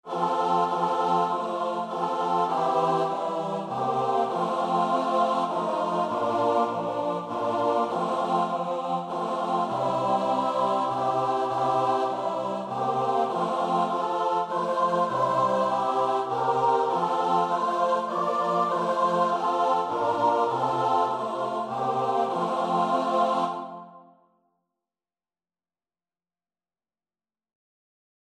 Free Sheet music for Choir (SATB)
3/4 (View more 3/4 Music)
Choir  (View more Easy Choir Music)
Classical (View more Classical Choir Music)